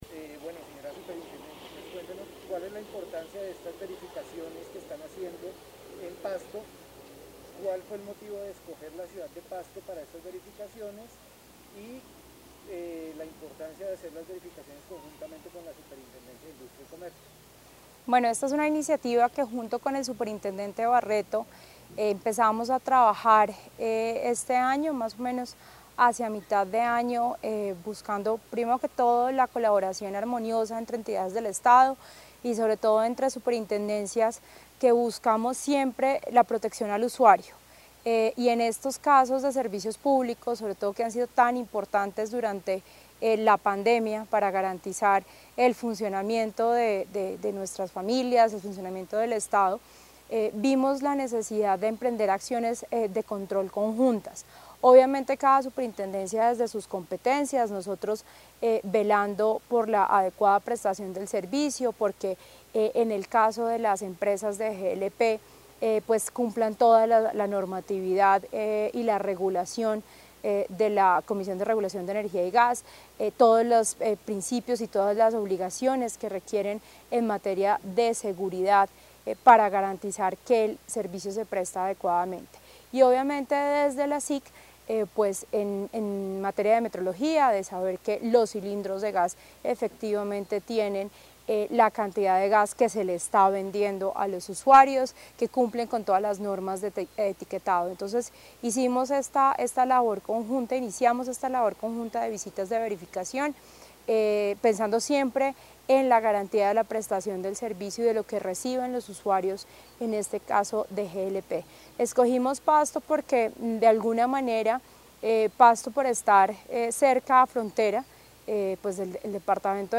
declaracions_superintendente_navendano_visitas_glp-24-nov-20.mp3